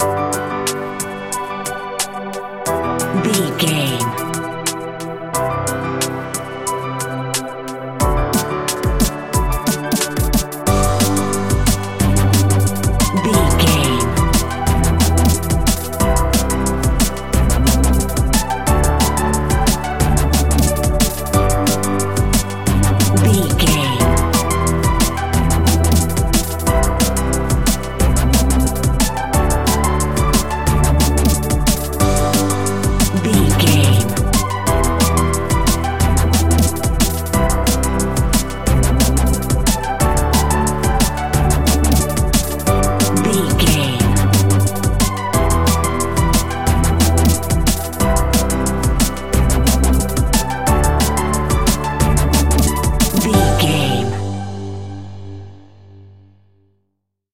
Ionian/Major
Fast
uplifting
lively
futuristic
hypnotic
industrial
frantic
drum machine
synthesiser
electronic
sub bass
synth leads
synth bass